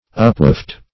\Up*waft"\